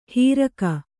♪ hīraka